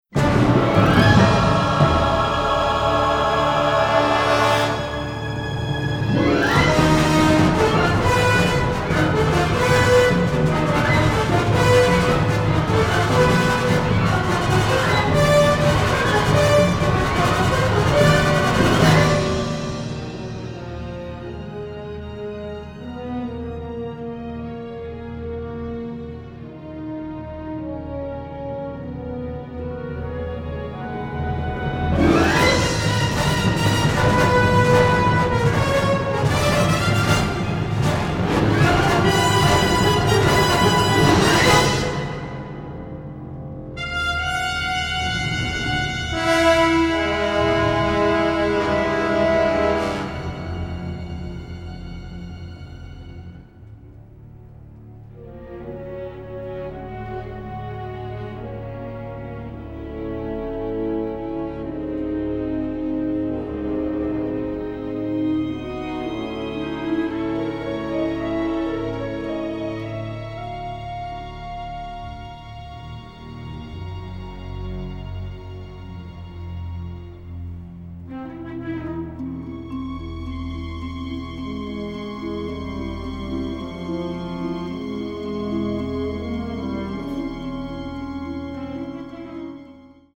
powerhouse, original orchestral material